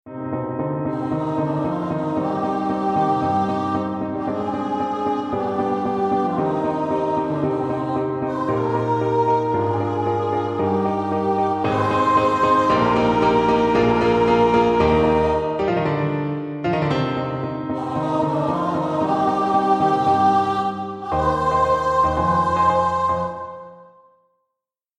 MIDIDédé, Edmond, Le Serment De L’Arabe, Chant Dramatique for voice and piano,